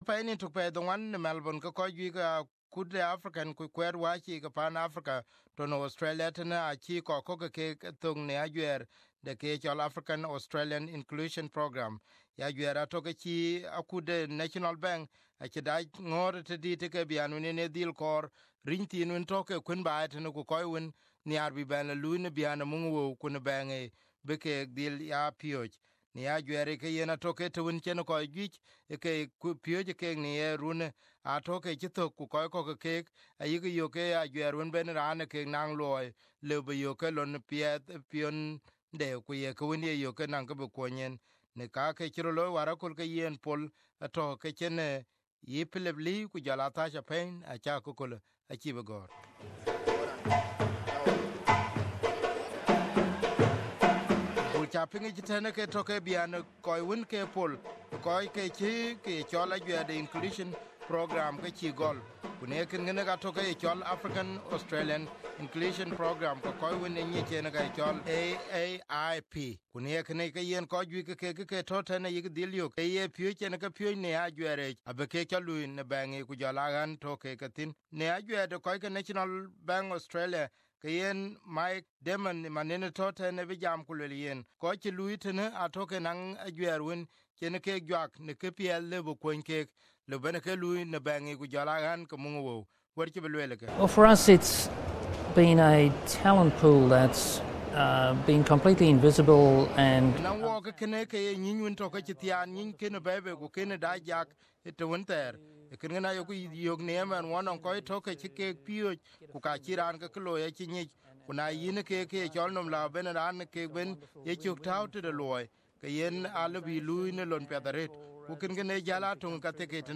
The drumming part of a graduation ceremony where African migrants and refugees were given a new start under an inclusion program that's seen a record number of participants graduate this year.
African drumming not usually heard in Melbourne's banking and finance quarter.